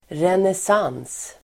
Uttal: [renes'an:s (el. -'ang:s)]